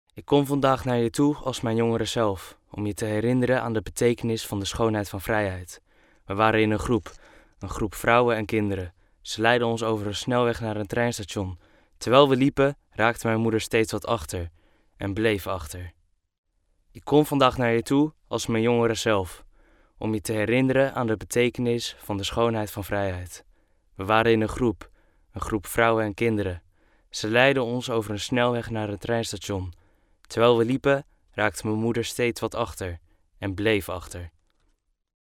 Dutch male voices